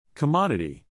logical /LO-gi-cal/